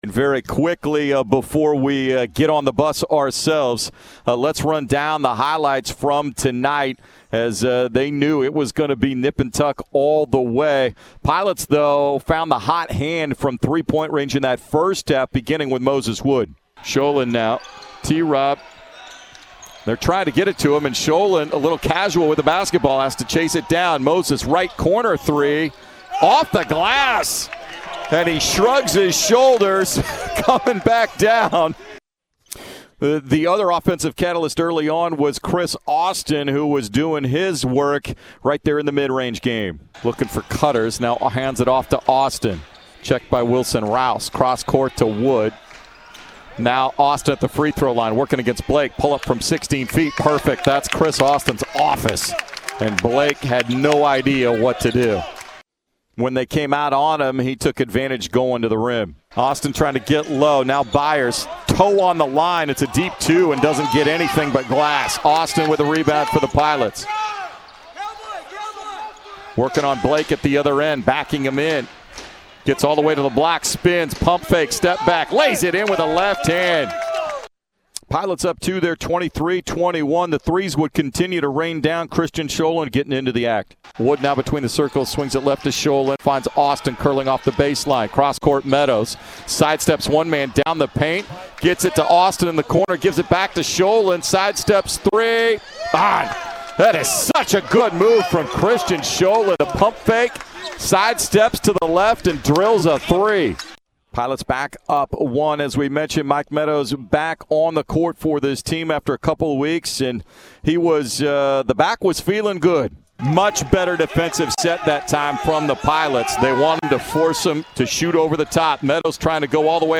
February 24, 2022 Radio highlights from Portland's 75-69 victory over Pacific.